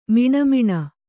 ♪ miṇa miṇa